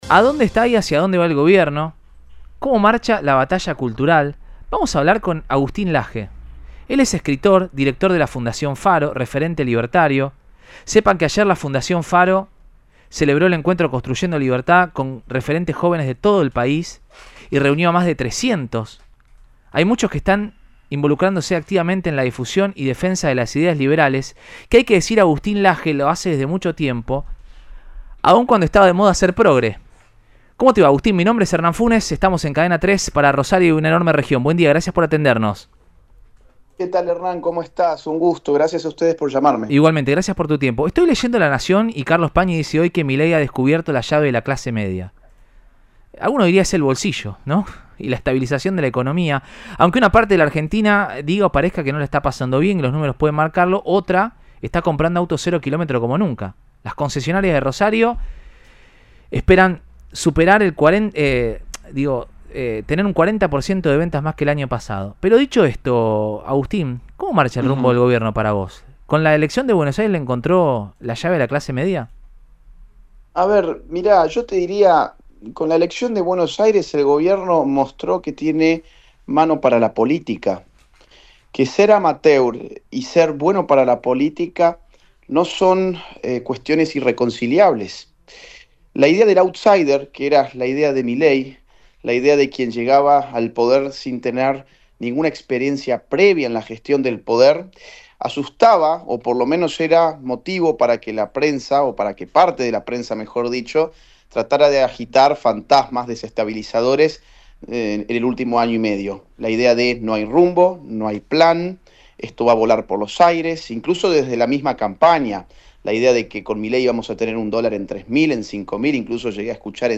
Entrevista a Agustín Laje en radio Cadena 3 Rosario - Fundación Faro Argentina
Entrevista de Cadena 3 Rosario a Agustín Laje, presidente ejecutivo de Fundación Faro.